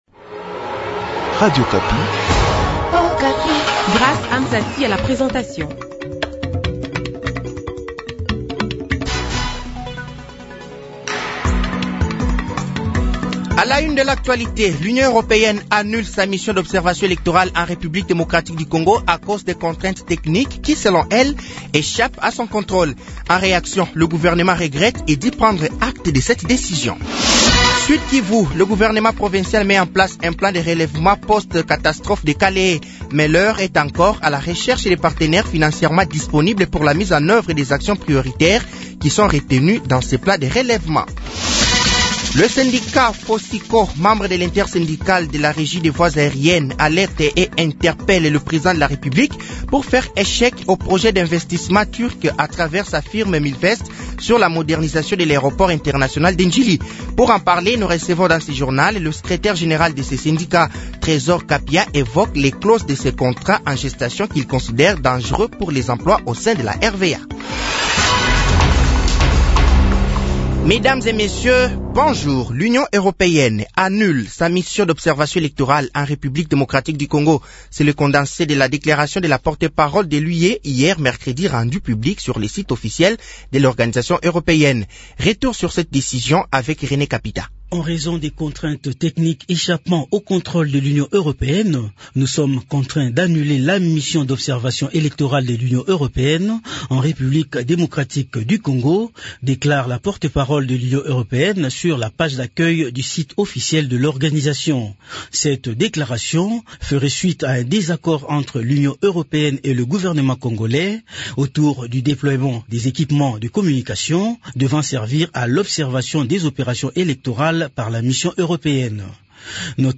Journal français de 6h de ce jeudi 30 novembre 2023